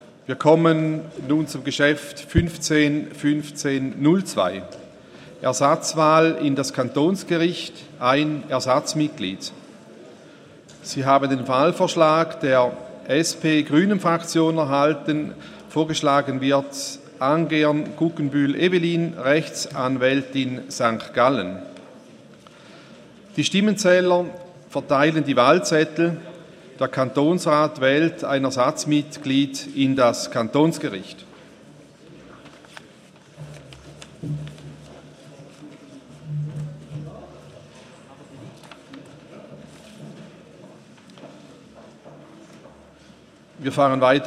14.9.2015Wortmeldung
Session des Kantonsrates vom 14. bis 16. September 2015